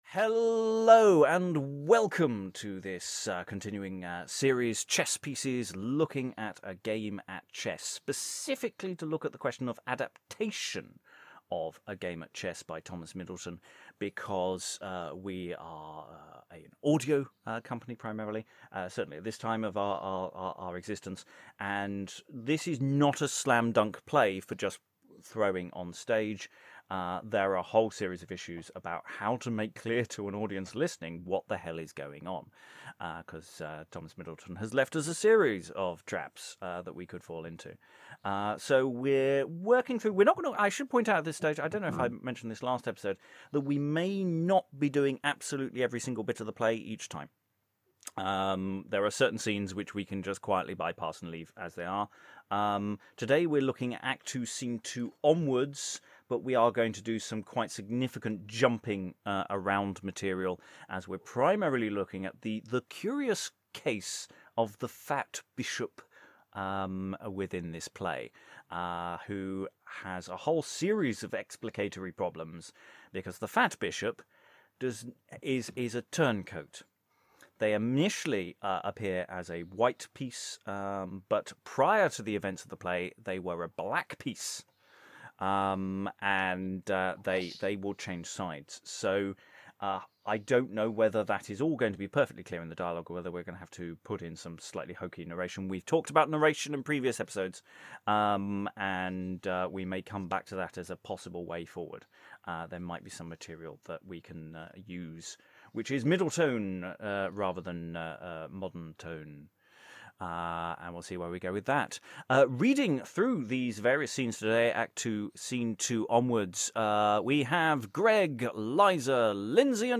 Chess Pieces: Exploring A Game at Chess by Thomas Middleton A planning session for our future productions of A Game at Chess by Middleton. We open with sections of Act Two, Scene Two and Act Three, Scene One - wrestling with the introduction of the Fat Bishop.